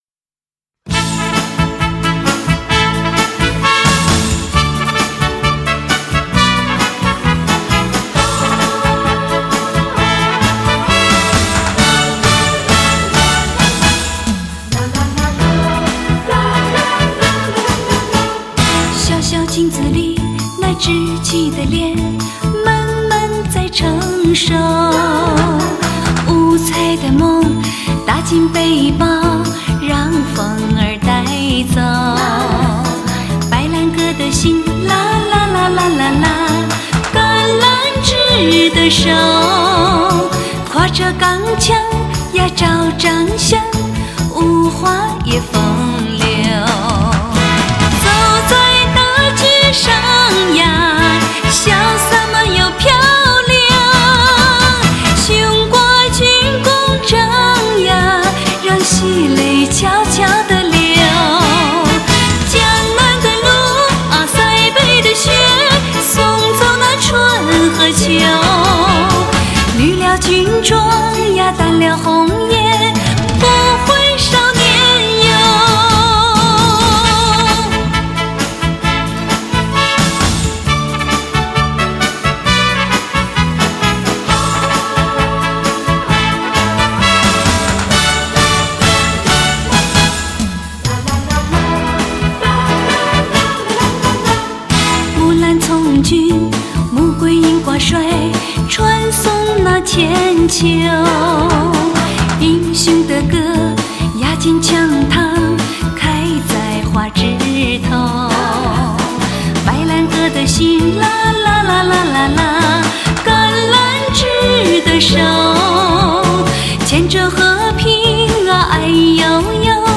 极致靓声，无与伦比的旋律，实力唱将，顶尖真空管录音，顶级发烧音乐至尊典藏。
全频清晰，音乐至上，无比传真，品质典范。